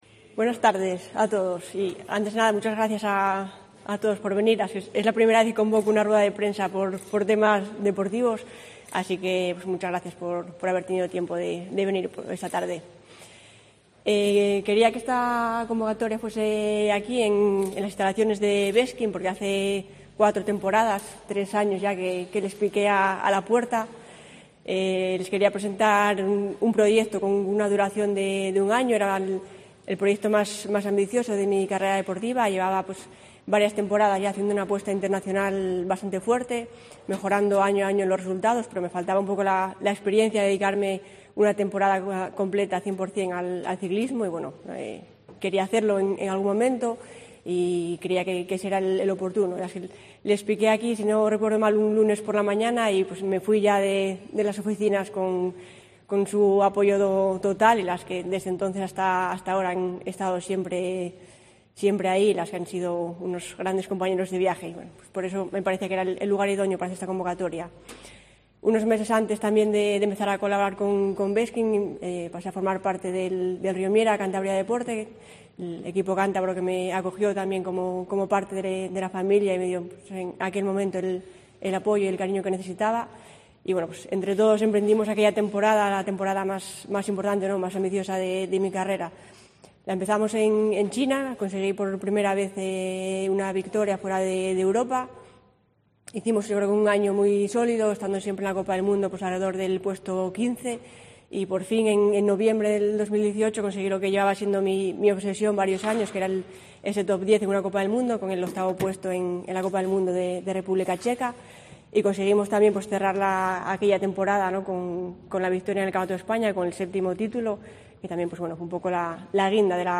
Rueda de prensa: Aida Nuño se retira